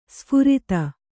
♪ sphurita